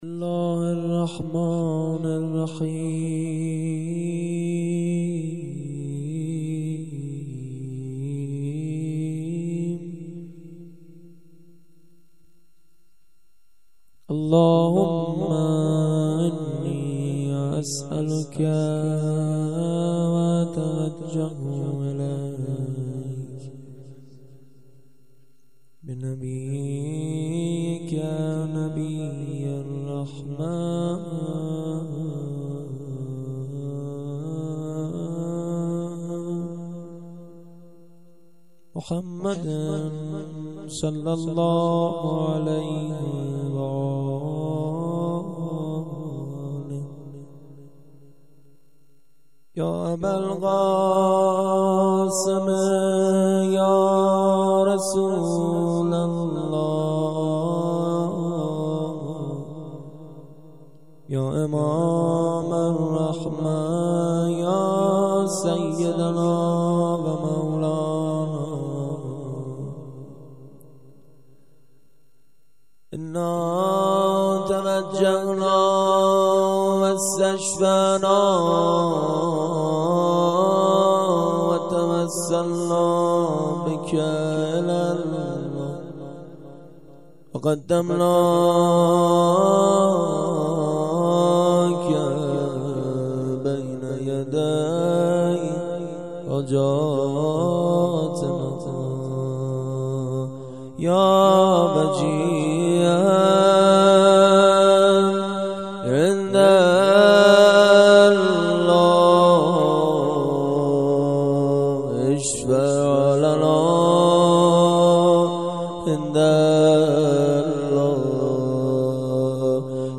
دعای توسل